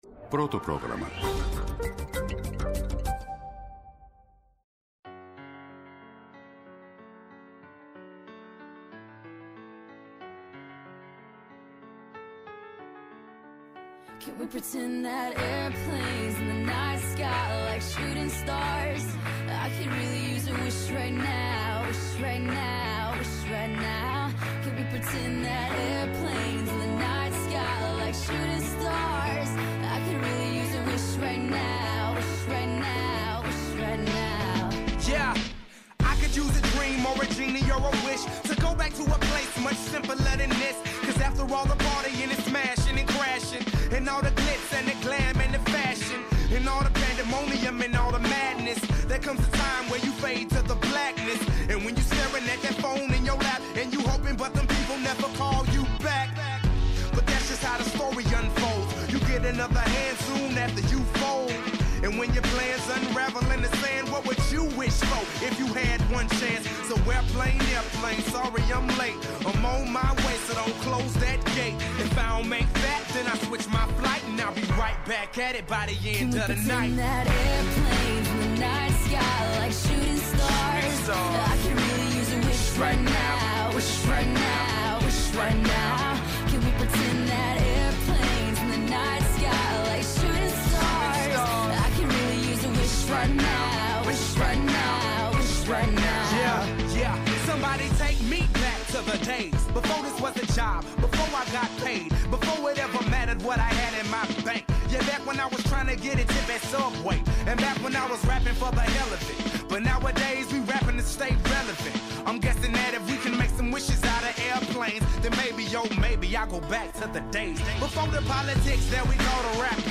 Στις Ψηφιακές Κυριακές σήμερα μιλούν οι καθηγητές: